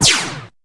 Synth Zaps
Synth Energy Zap, Sharp